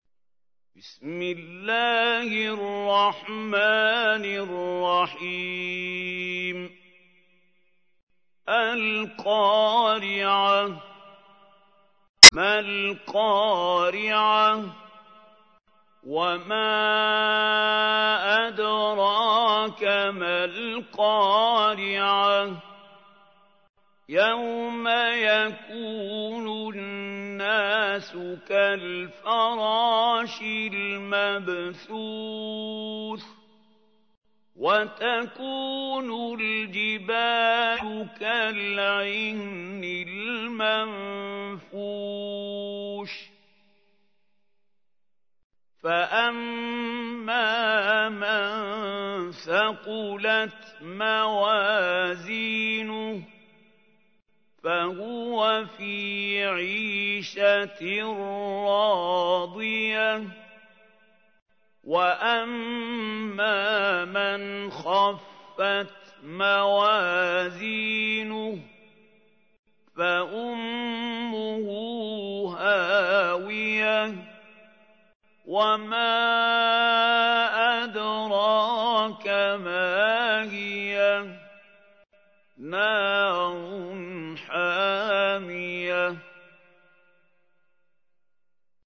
تحميل : 101. سورة القارعة / القارئ محمود خليل الحصري / القرآن الكريم / موقع يا حسين